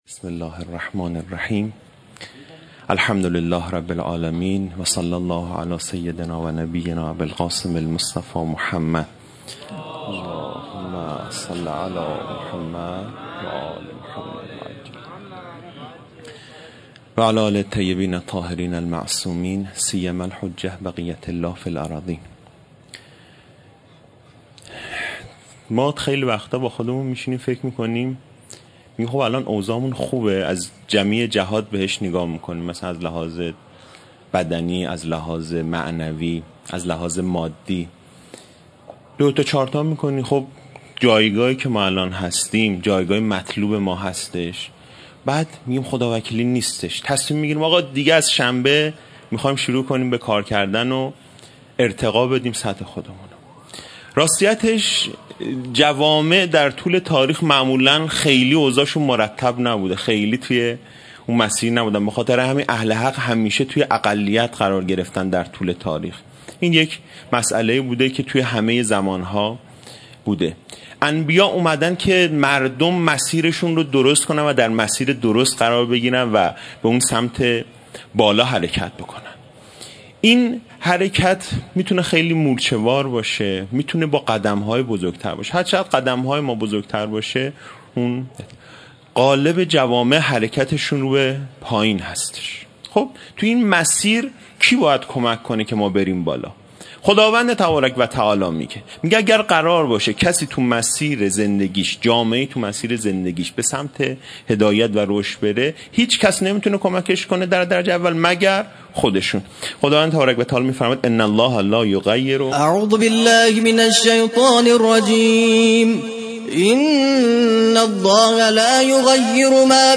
شب اول محرم ۱۳۹۹